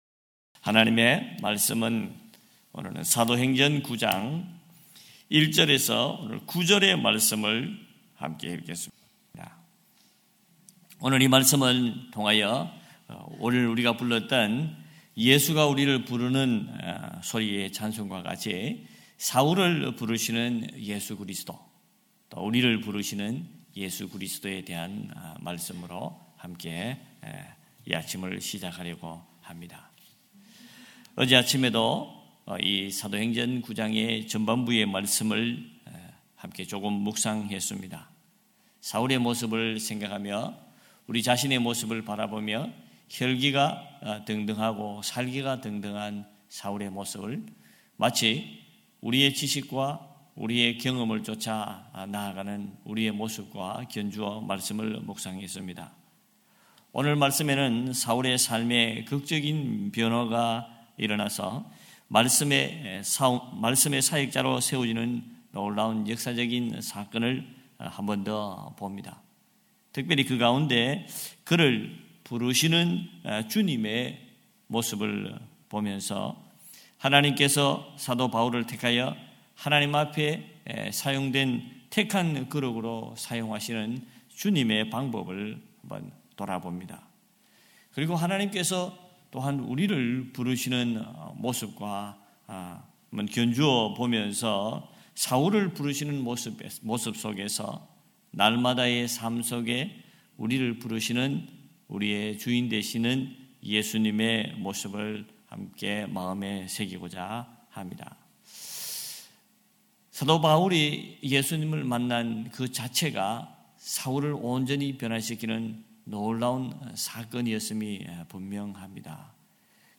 4.11.2026 새벽예배 사도행전 9장 1-9절